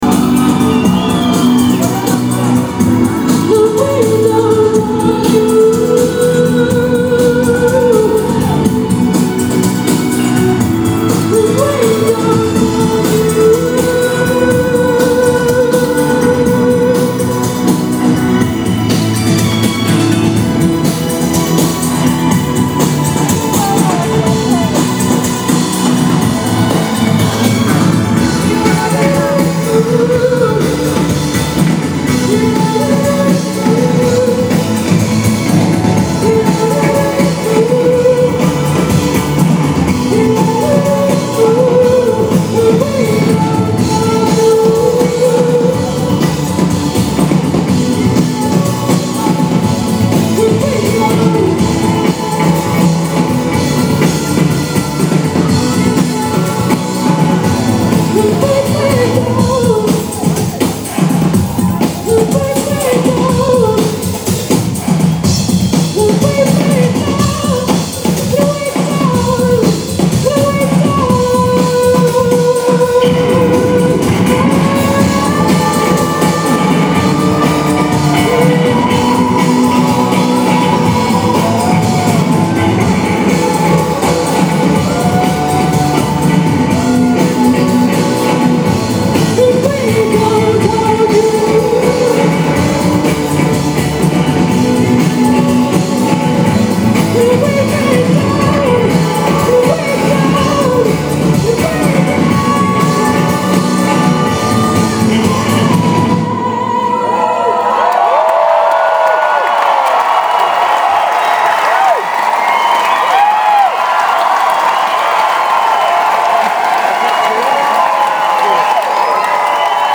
live at the 9.30 Club, DC